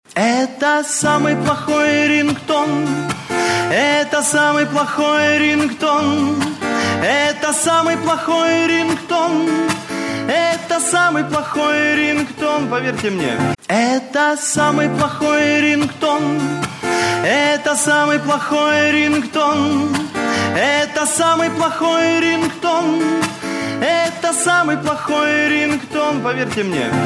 забавные
веселые
смешные
Песня из шоу